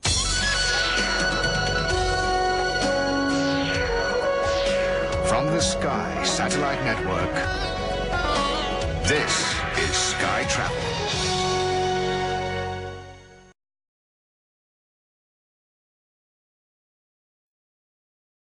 Jingle | ANTENNE